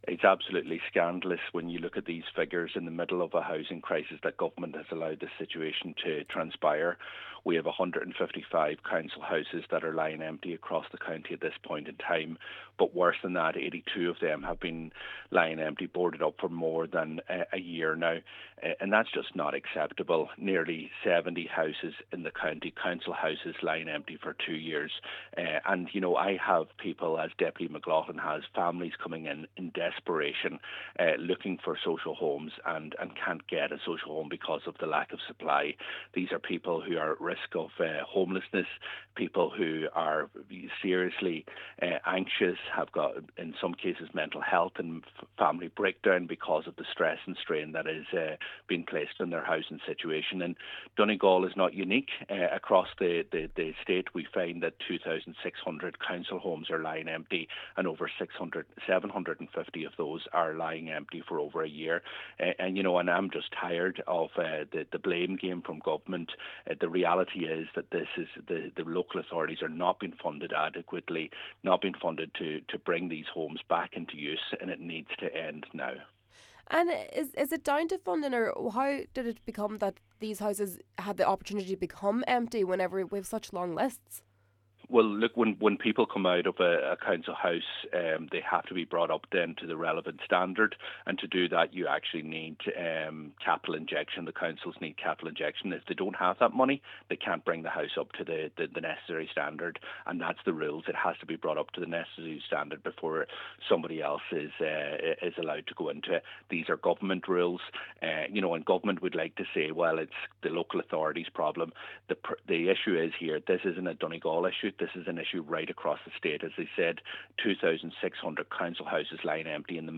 He added that while the crisis is compounded in Donegal by crumbling homes, this can’t be labelled as a county problem: